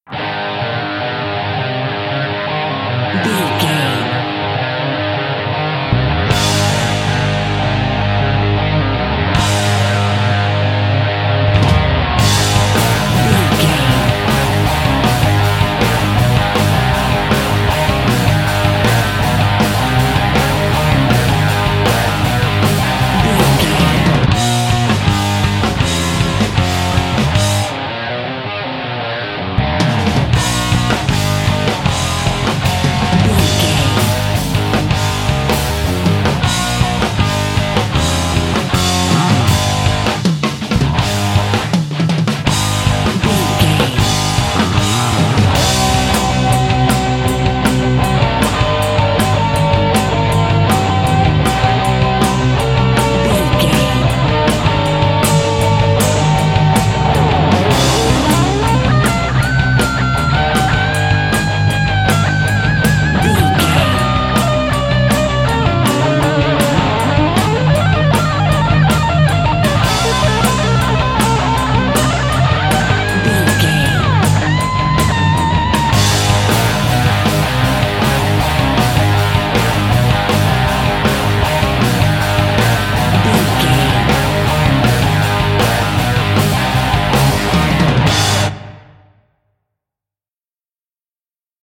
Aeolian/Minor
A♭
Fast
drums
electric guitar
hard rock
lead guitar
bass
aggressive
energetic
intense
nu metal
alternative metal